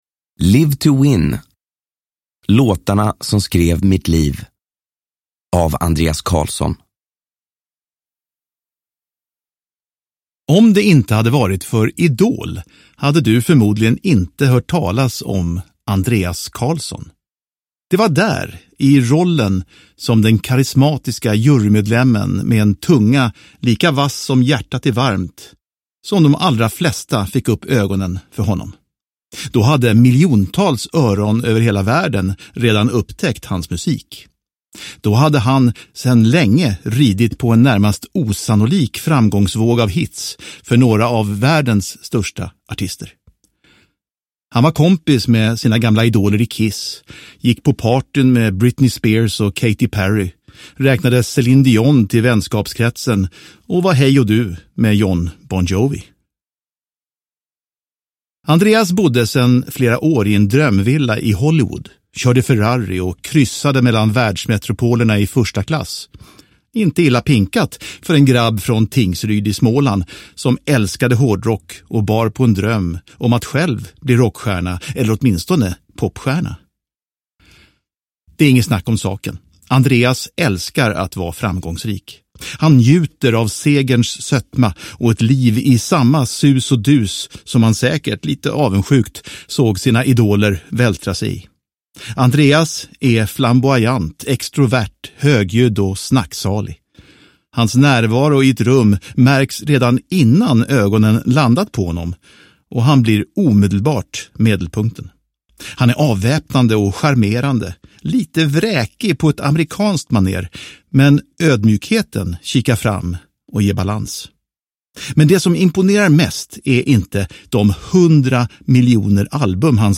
Live to Win - Låtarna som skrev mitt liv – Ljudbok – Laddas ner
Uppläsare: Andreas Carlsson